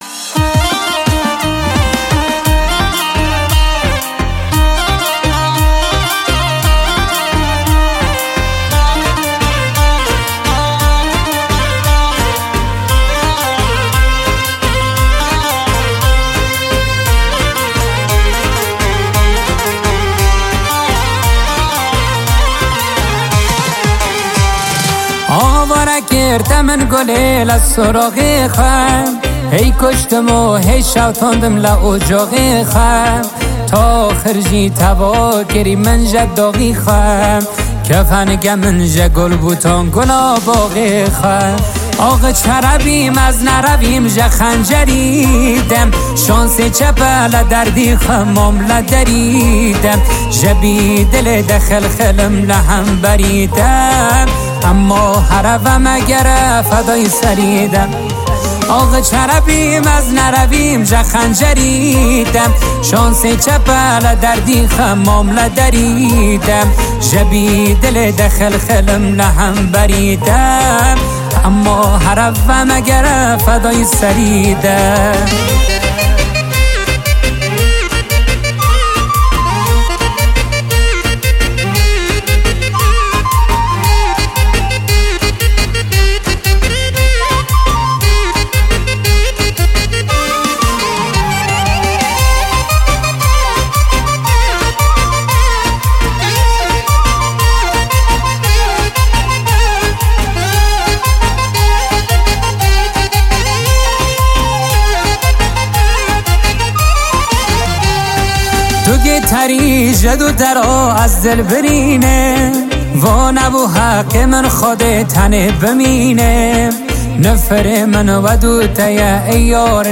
موزیک کرمانجی